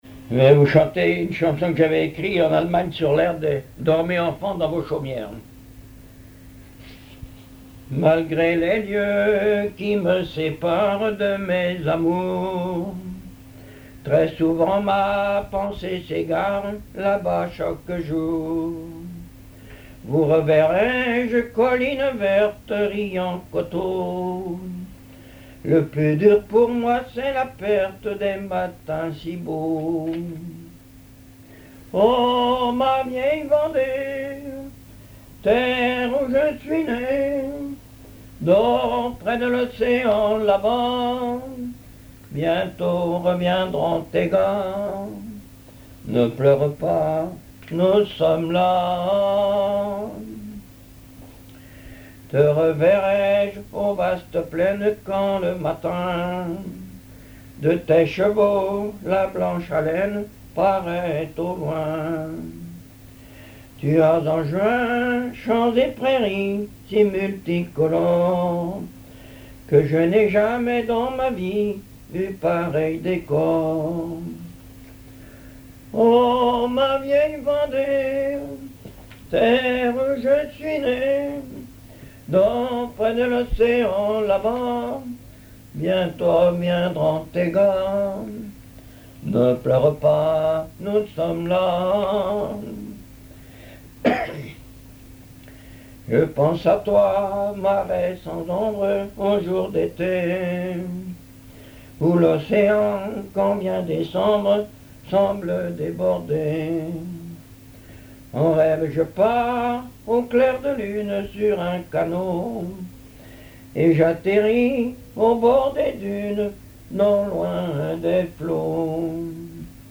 histoires drôles, poème, chansons populaires
Catégorie Pièce musicale inédite